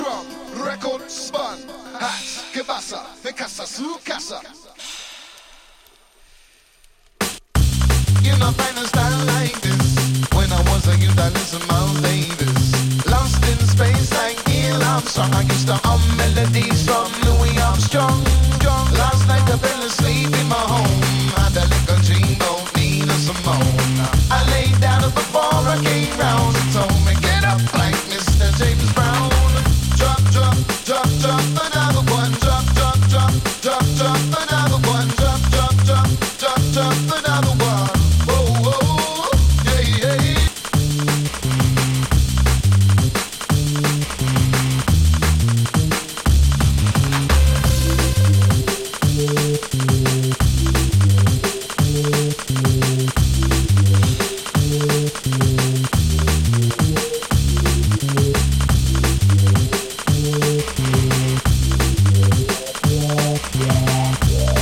Drum N Bass